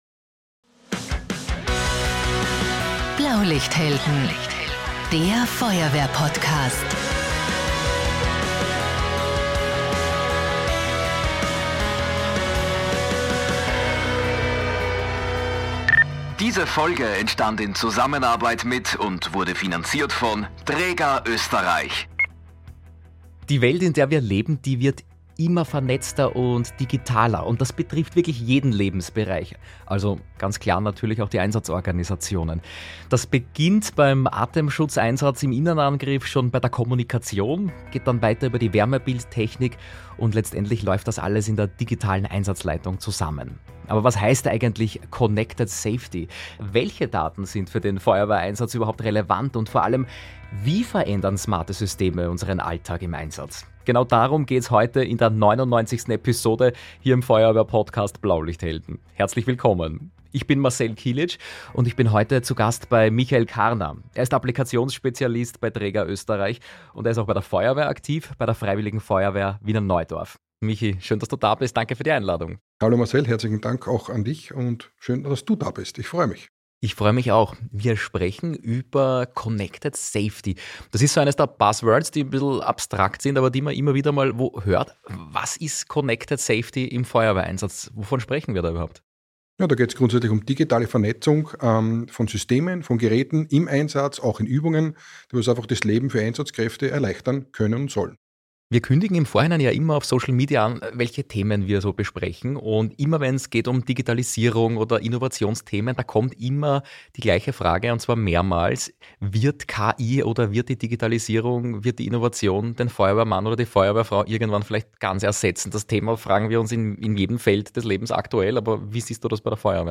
In dieser Folge melden wir uns „live“ von der großen Award-Show im Ö3-Haus am Wiener Küniglberg.